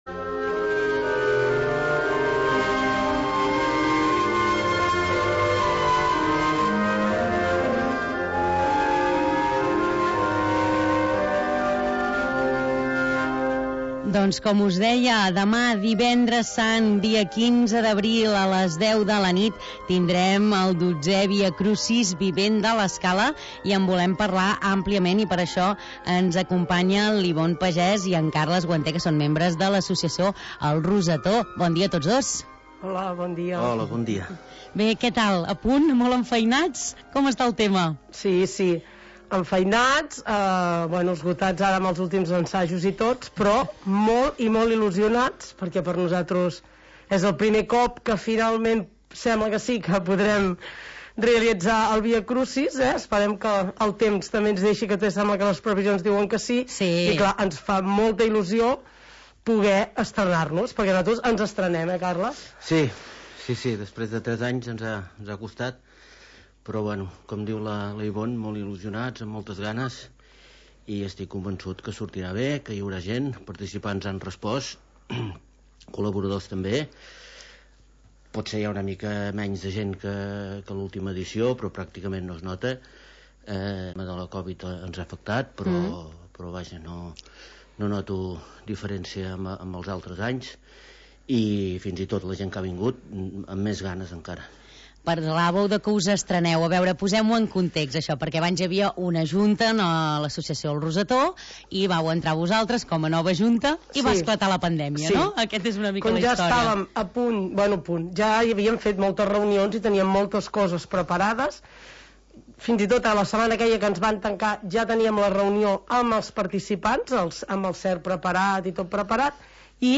Entrevista Viacrucis l’Escala 2022